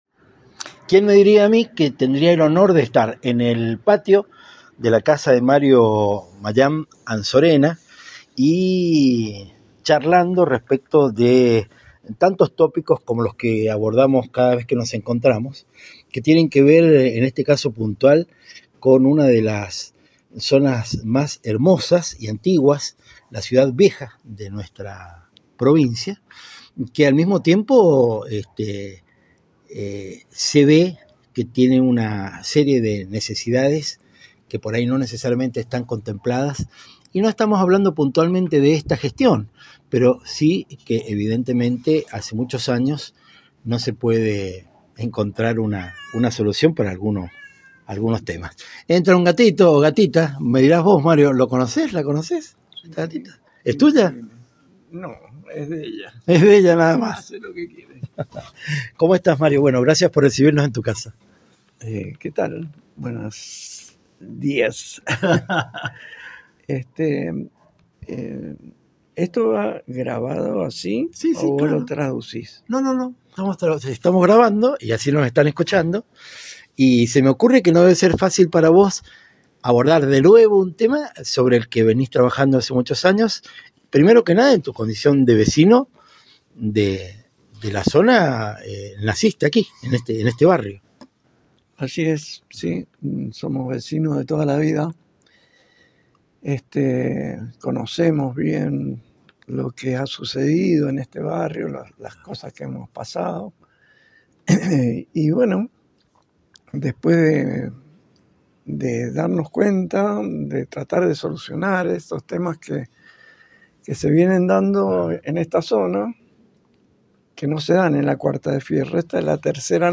Comienzo este ciclo con la entrevista a uno de los grandes artistas menducos que admiro desde hace años y espero que me acompañes en esta empresa que ha de sucederse semana tras semana.